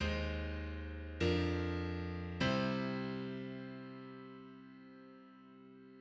Play sP-dP-T
A sequence of A-major, B-major, and C-major chords, for example, could be neatly interpreted as a subdominant (sP) to dominant (dP) to tonic (T) progression in C-major, a reading...not without support in certain late-Romantic cadences.
SP-dP-T_cadence_in_C.mid.mp3